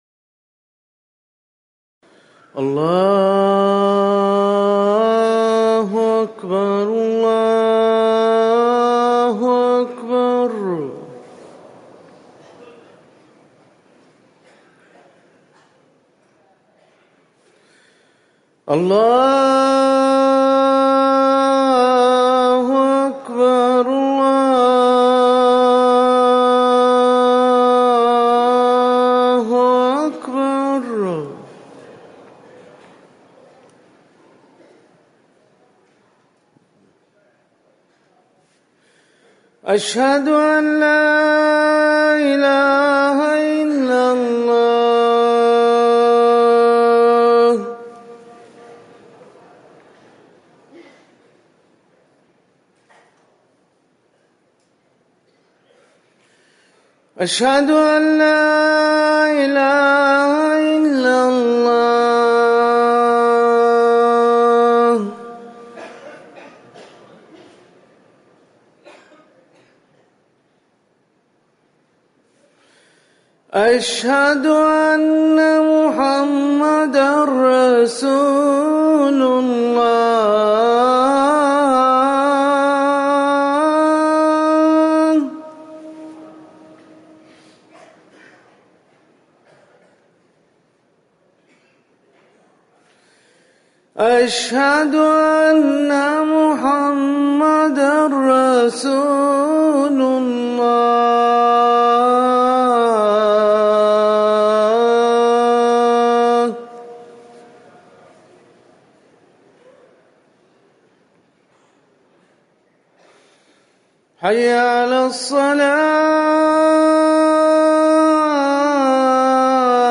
أذان الفجر الأول
تاريخ النشر ١٦ صفر ١٤٤١ هـ المكان: المسجد النبوي الشيخ